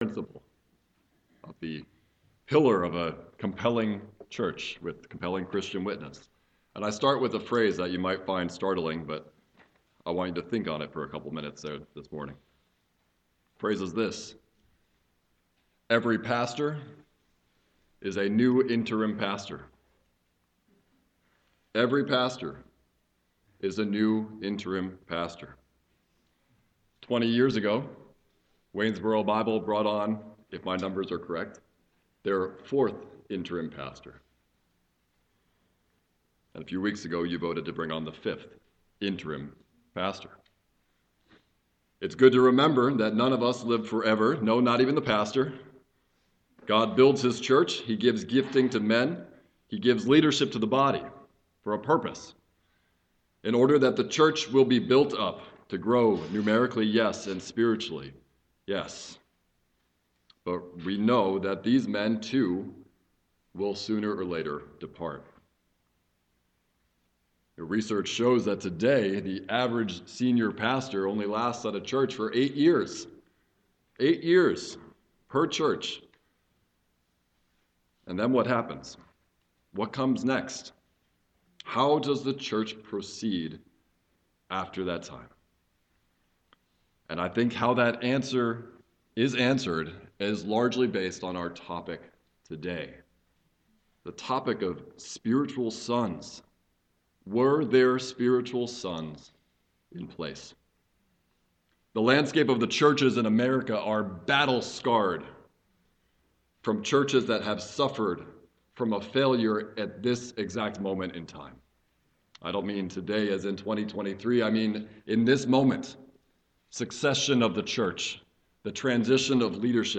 Morning Worship
Sermon